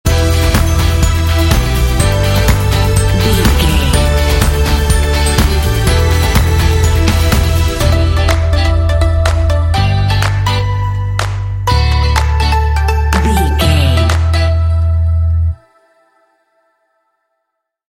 Aeolian/Minor
cheerful
electric guitar
bass guitar
strings
drums
synthesiser
synth-pop
indie